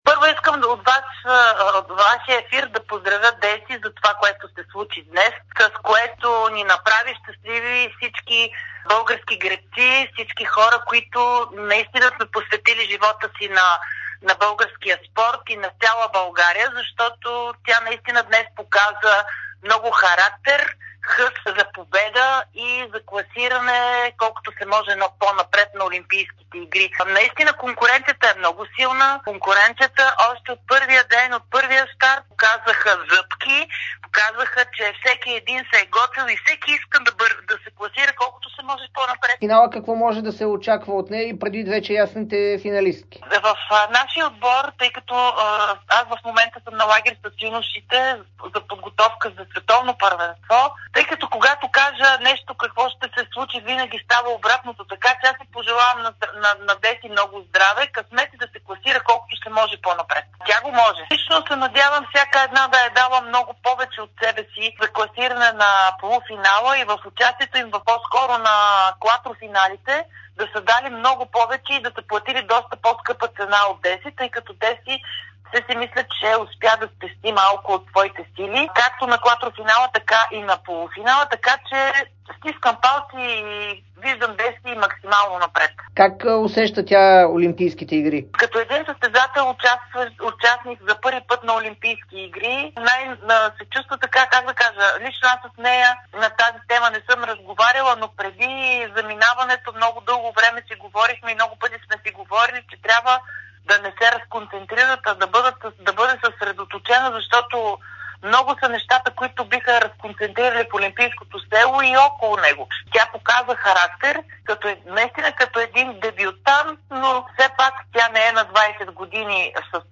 Олимпийската шампионка от Пекин 2008 Румяна Нейкова говори пред dsport и Дарик радио по повод класирането на Десислава Ангелова във финала на 2000 метра скиф.